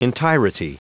Prononciation du mot entirety en anglais (fichier audio)
Prononciation du mot : entirety